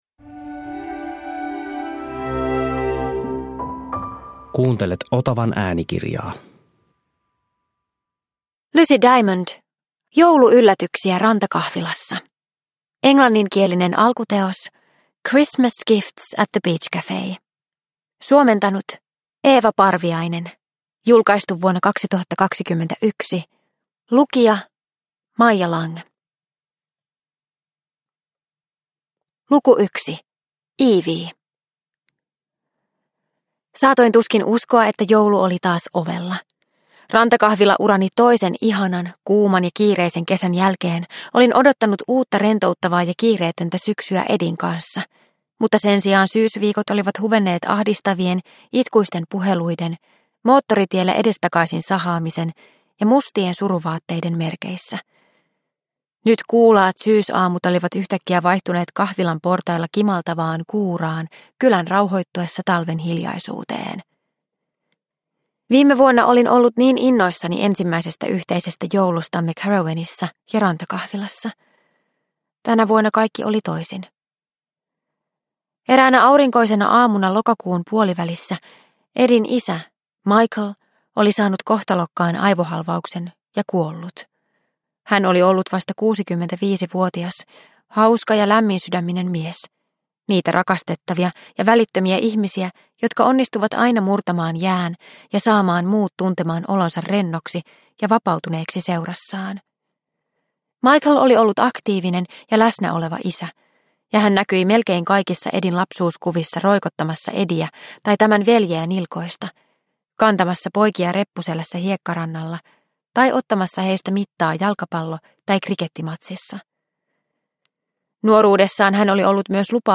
Jouluyllätyksiä Rantakahvilassa – Ljudbok – Laddas ner